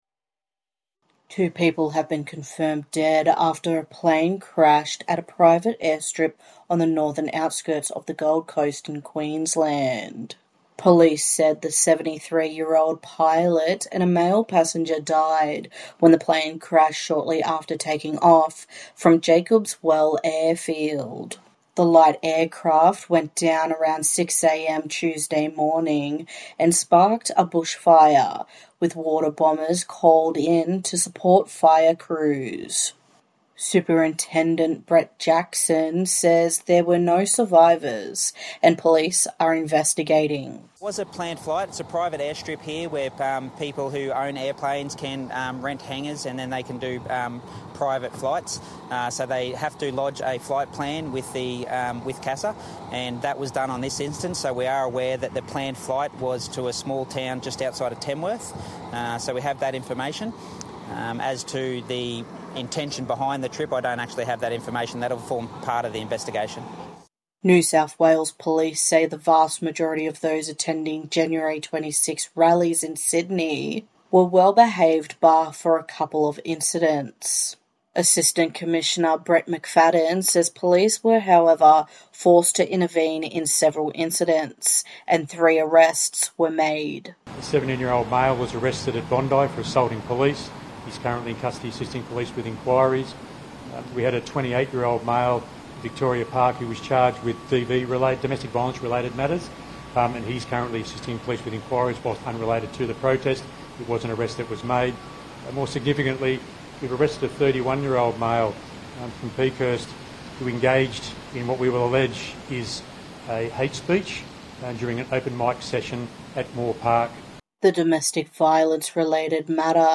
NITV Radio News - 28/01/2026